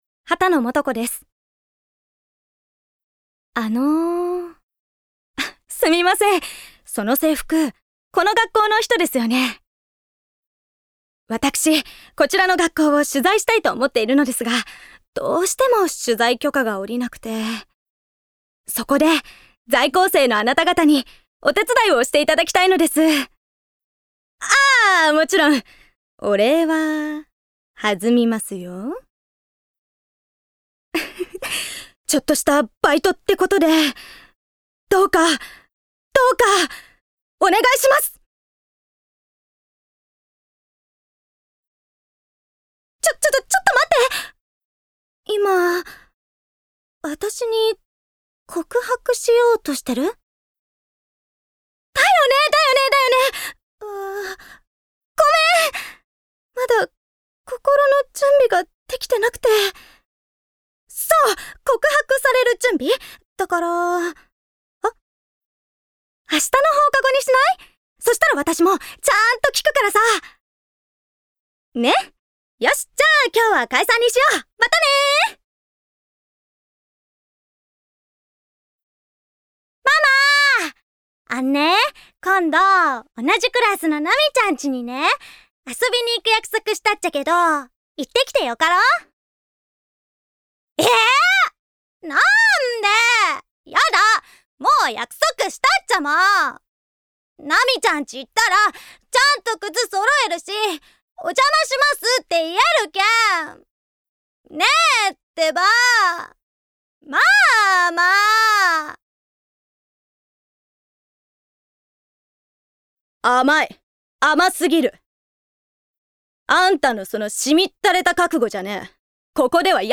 誕生日： 12月18日 血液型： A型 身 長： 156cm 出身地： 福岡県 趣味・特技： スポーツ観戦・シュークリーム食べ比べ・バレーボール・回転包み 資格： 医療事務認定実務者 方言： 博多弁
VOICE SAMPLE